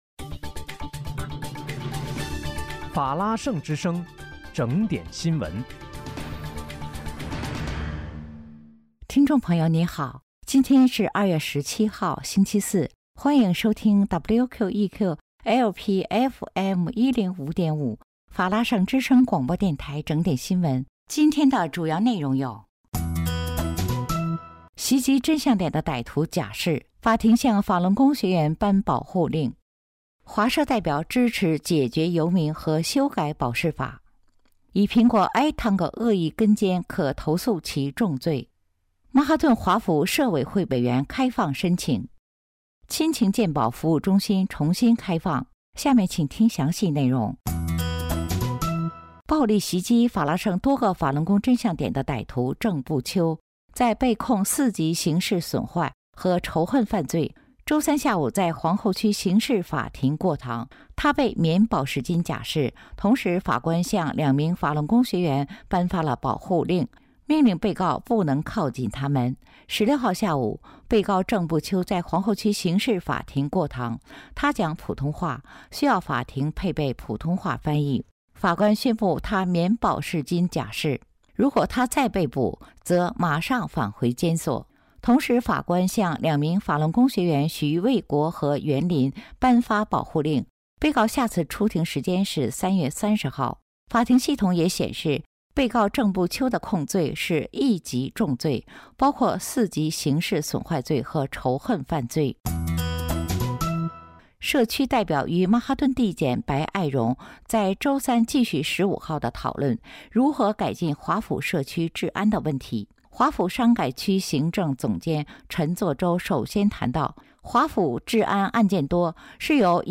2月17日(星期四）纽约整点新闻
听众朋友您好！今天是2月17号，星期四，欢迎收听WQEQ-LP FM105.5法拉盛之声广播电台整点新闻。